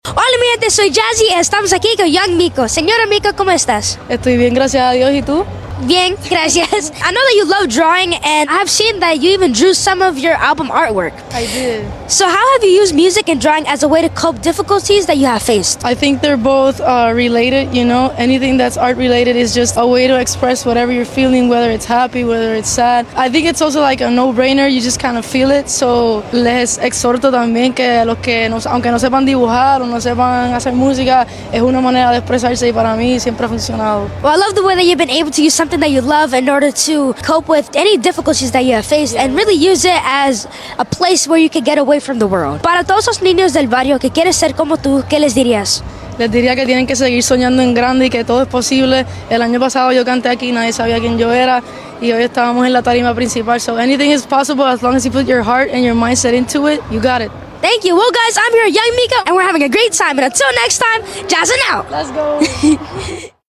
Pequeña entrevista a Young Miko